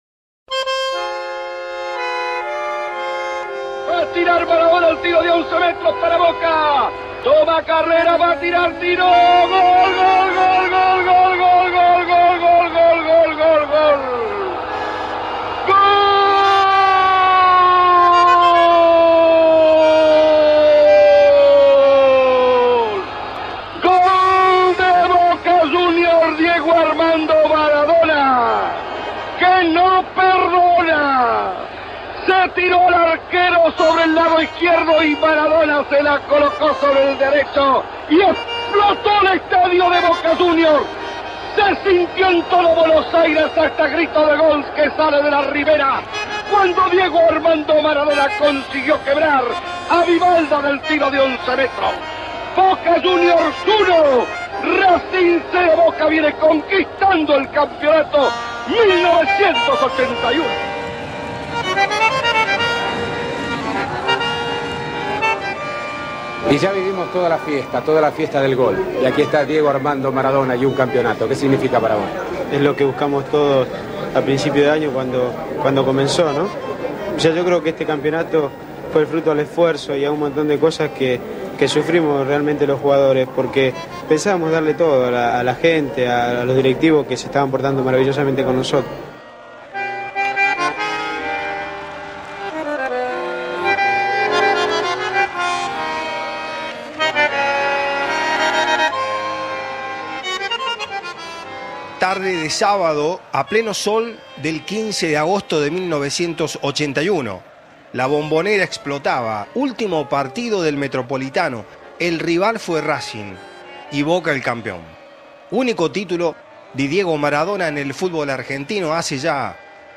A 40 años del Metropolitano 1981, el primero, único y, por ende, último título de Diego Maradona con Boca. El recuerdo de aquella campaña, en la voz de sus protagonistas.